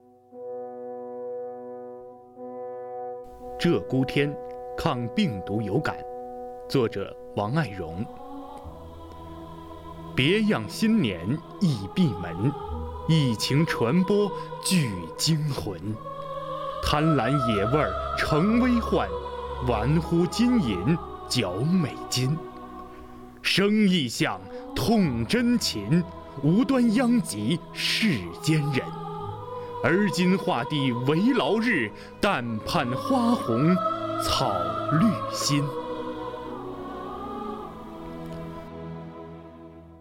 为声援战斗在一线的工作人员，鼓舞全区人民抗击疫情的信心和决心，丰南文化馆、丰南诗歌与朗诵协会继续组织诗歌与诵读工作者、爱好者共同创作录制诵读作品。
朗诵